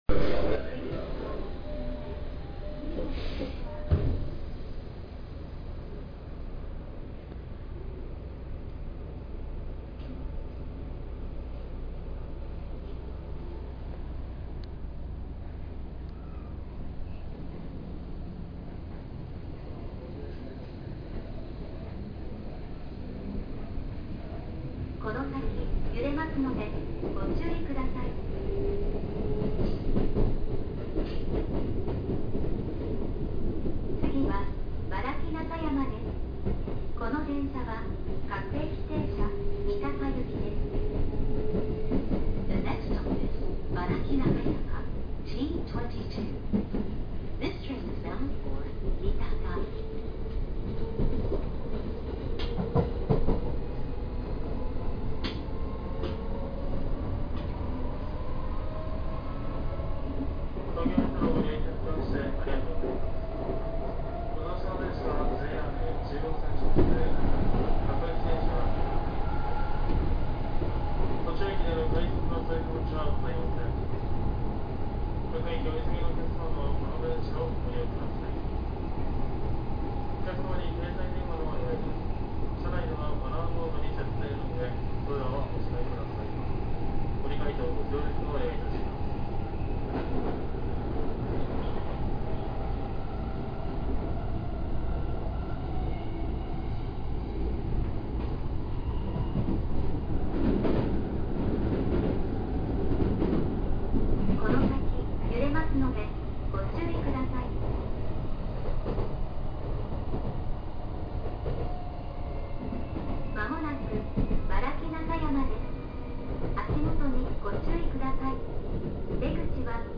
・05系（６・７次車更新）走行音
そのため、静粛性には優れる一方でモーター音的には少々物足りない印象になってしまっています。